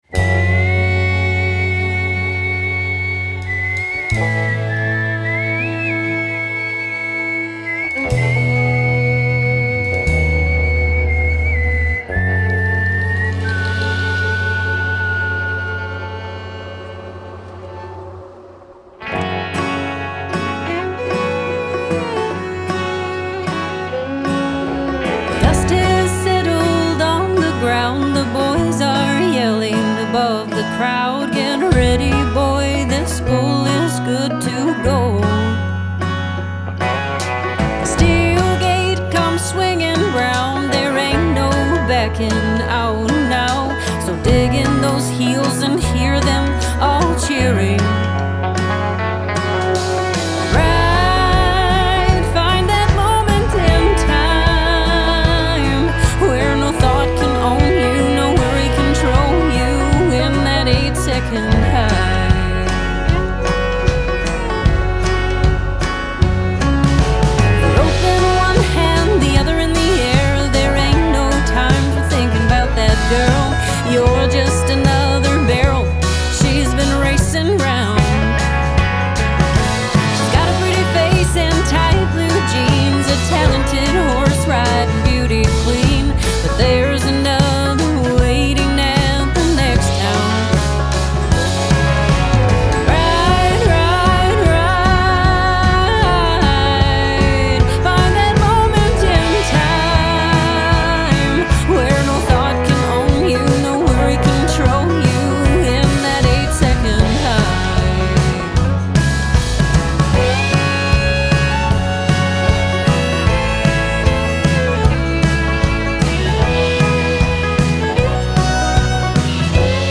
folk-country singer-songwriter
With vivid storytelling and dynamic production